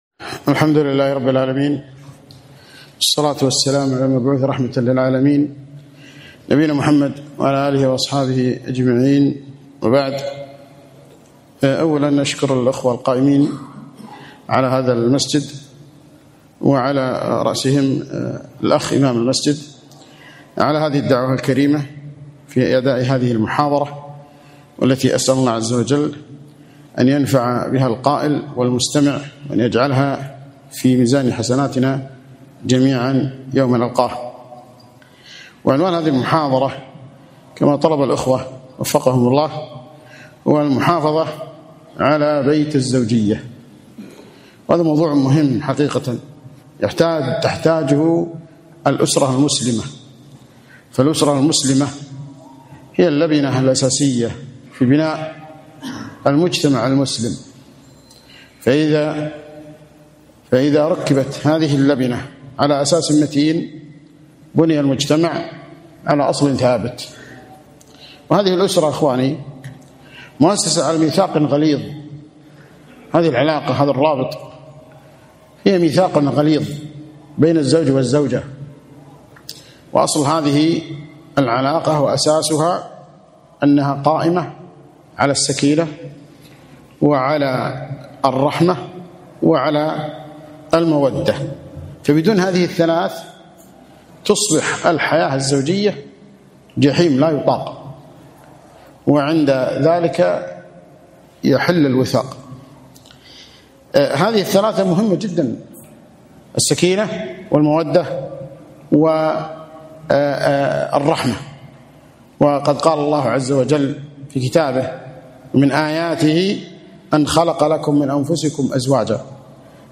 محاضرة - المحافظة على بيت الزوجية